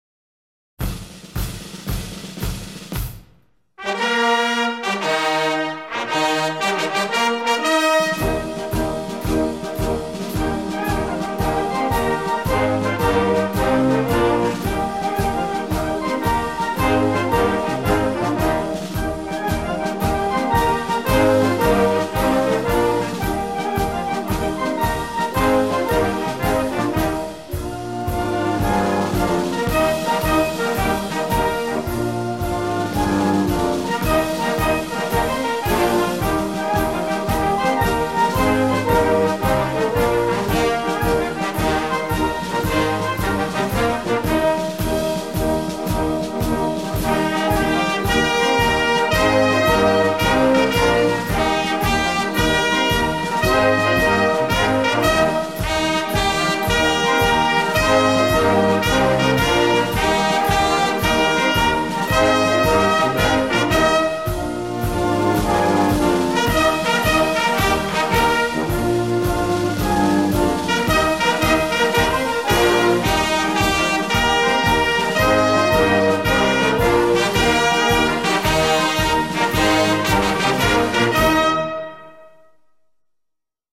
Regimental Quick March